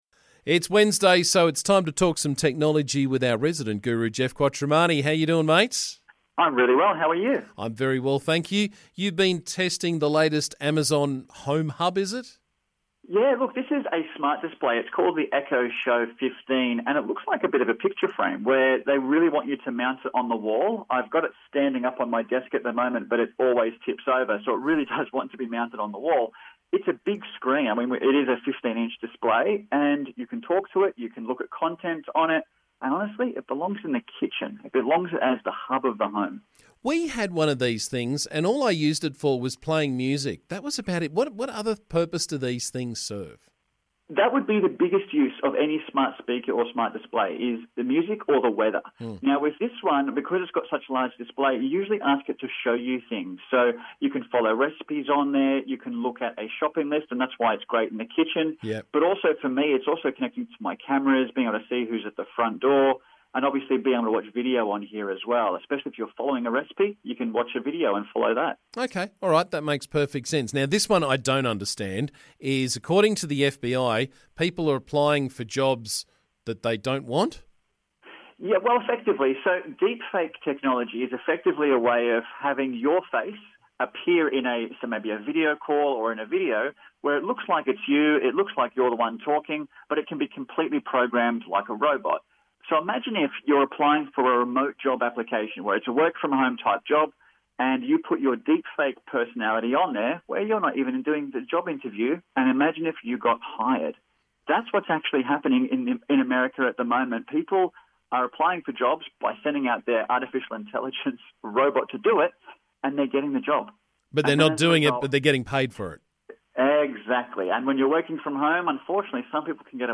Every Wednesday on the 100% Hunter Breakfast Show